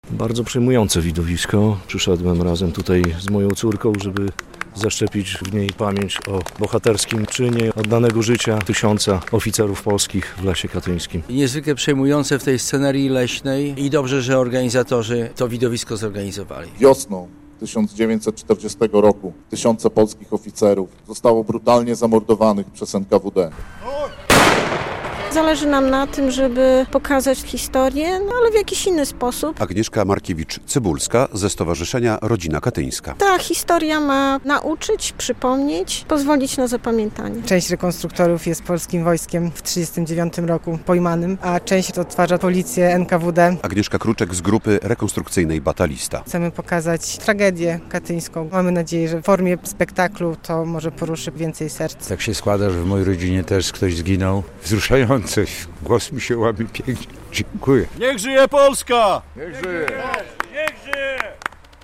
W Białymstoku około 200 osób obejrzało spektakl słowno-muzyczny pt. "Katyń 1940". W Parku Zwierzynieckim, koło Pomnika Katyńskiego, aktorzy z grup rekonstrukcyjnych odtworzyli wydarzenia, które miały miejsce 85 lat temu.